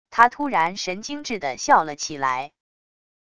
他突然神经质的笑了起来wav音频生成系统WAV Audio Player